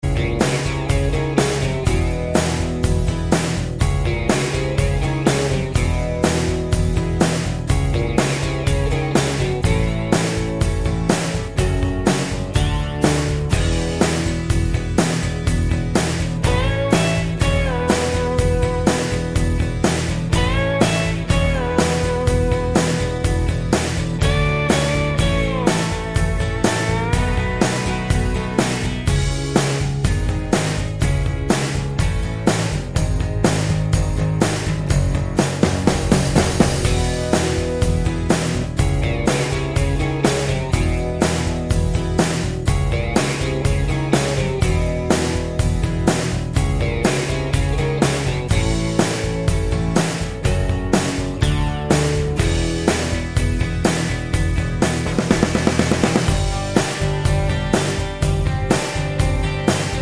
No Two Part Harmonies Pop (1980s) 3:34 Buy £1.50